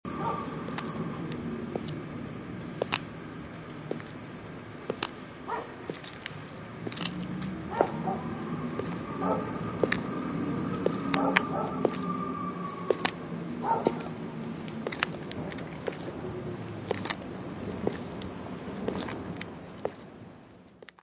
Angoise et epouvante : bruits et situations = Anguish and terror : noises and situations = Angustia y terror : ruidos y situaciones
Physical Description:  1 disco (CD) (ca. 63 min.) : stereo; 12 cm + folheto